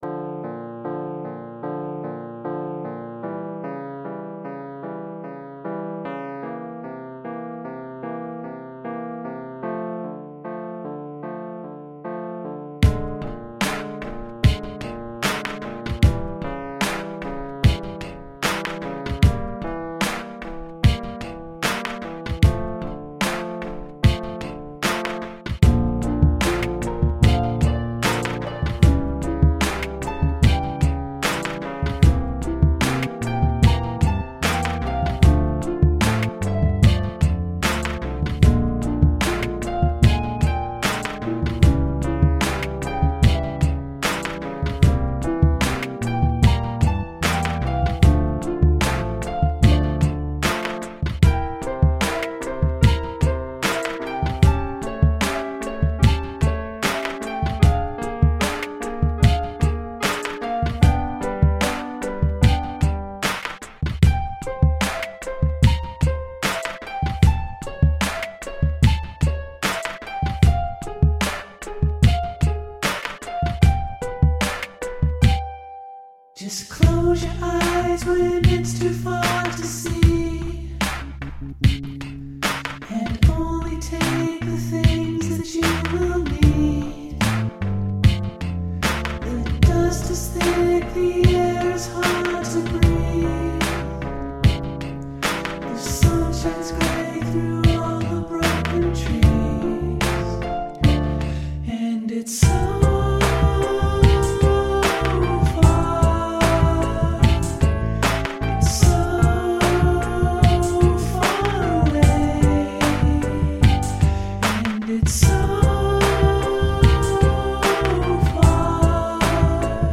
Groove soaked ambient chill.
Alt Rock, Rock, Remix